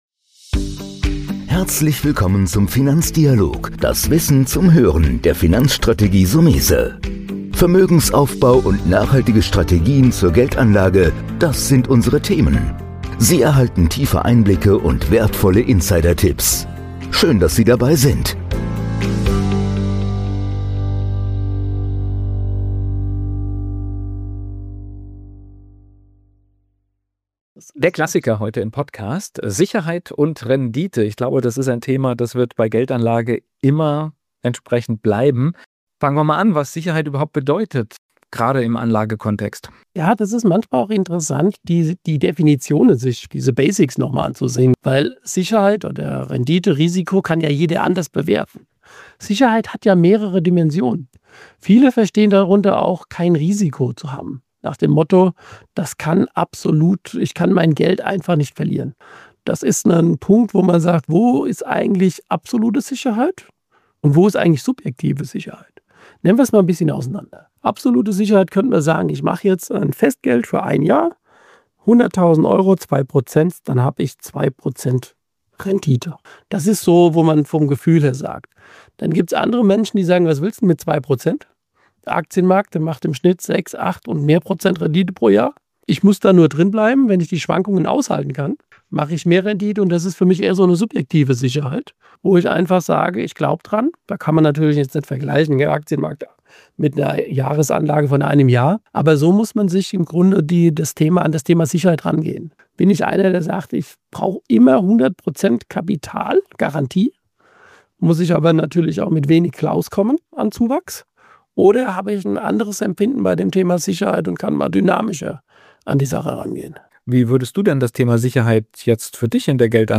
Moderation